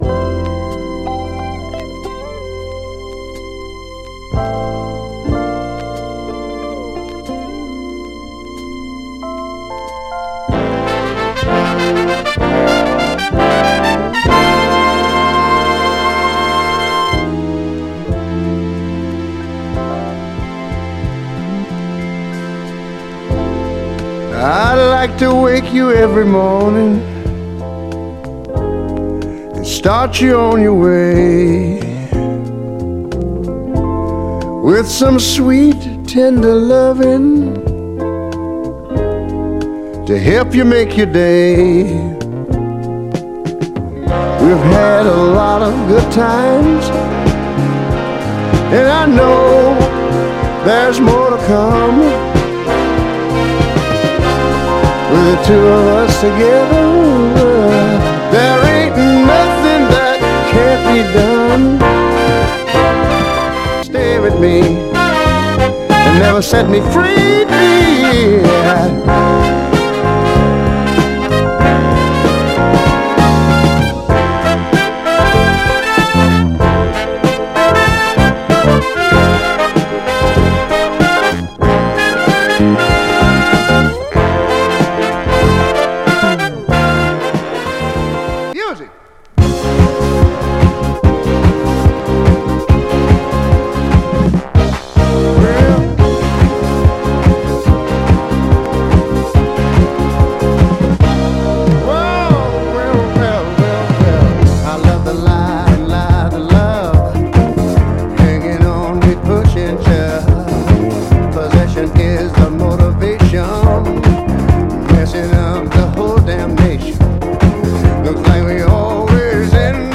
疾走シンセ炸裂のフュージョン・ブギーなヴァージョンでガラージ系DJにもプレイされる人気のテイクです。
高揚感を煽るブラス隊に込み上げヴォーカルが染み渡る、クロスオーヴァーなメロウ・ダンサー。
※試聴音源は実際にお送りする商品から録音したものです※